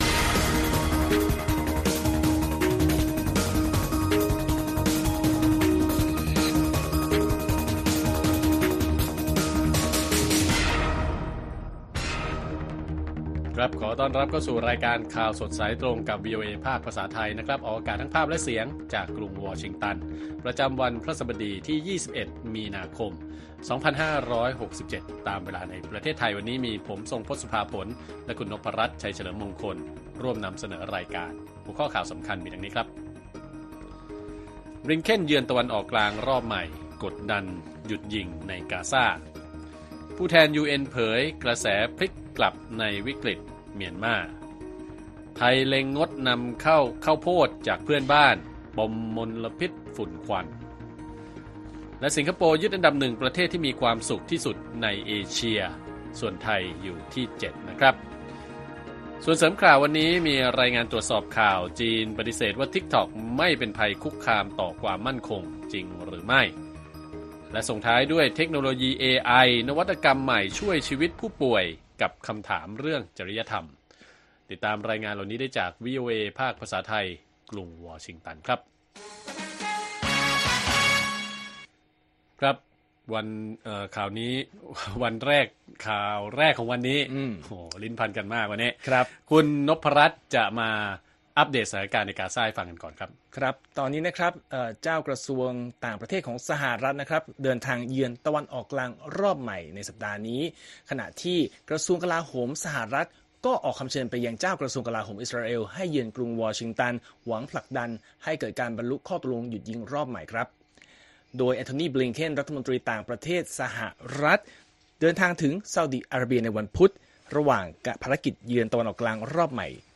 ข่าวสดสายตรงจากวีโอเอไทย วันพฤหัสบดีที่ 21 มีนาคม 2567 6:30 – 7:00 น.